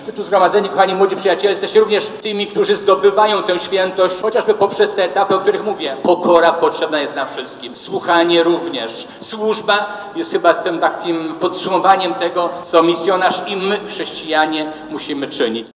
W kościele, wypełnionym po brzegi